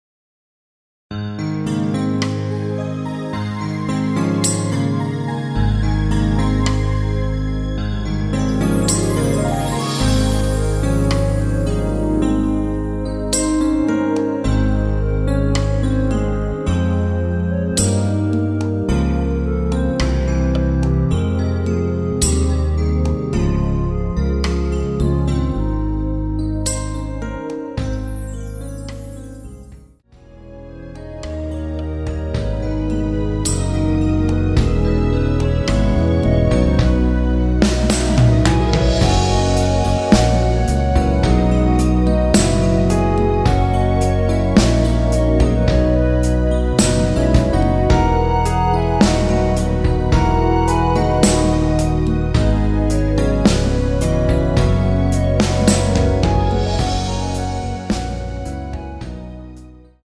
원키에서(-2)내린 멜로디 포함된 MR입니다.
Ab
앞부분30초, 뒷부분30초씩 편집해서 올려 드리고 있습니다.
중간에 음이 끈어지고 다시 나오는 이유는